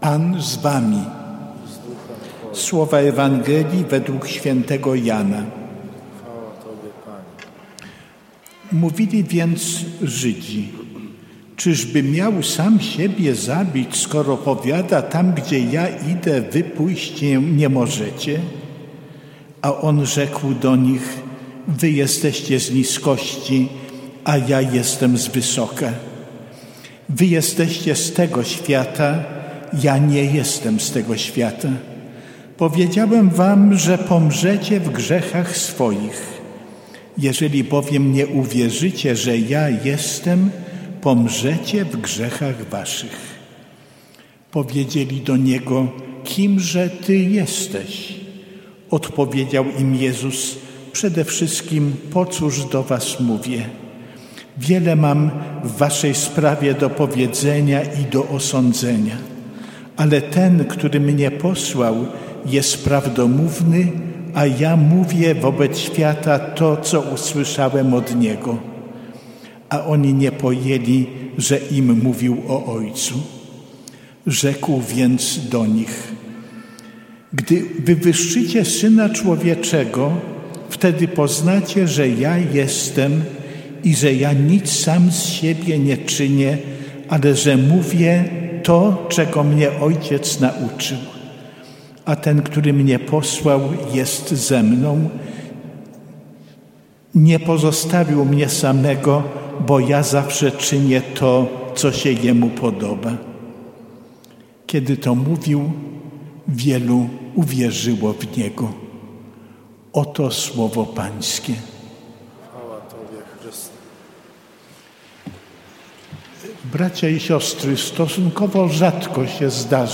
W tym miejscu zamieścimy nagrania czterech dni rekolekcji
w Świątyni Opatrzności Bożej
Rekolekcje dzień pierwszy